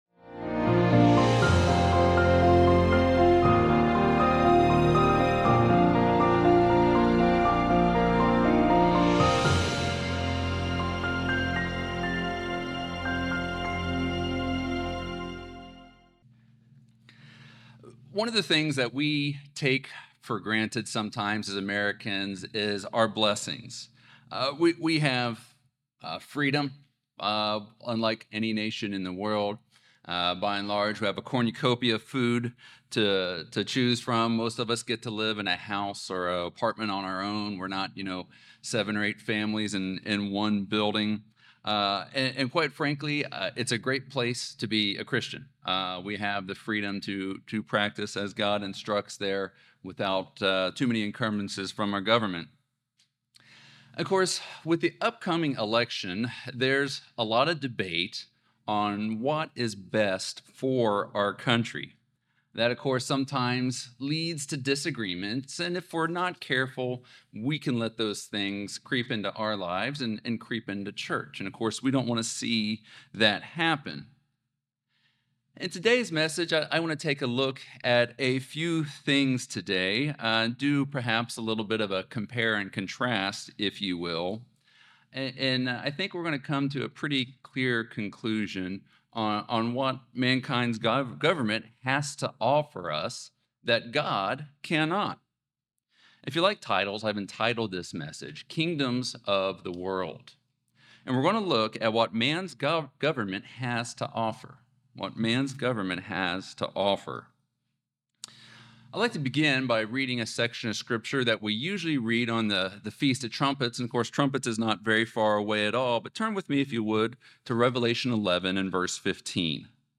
Sermons
Given in Charlotte, NC Columbia, SC Hickory, NC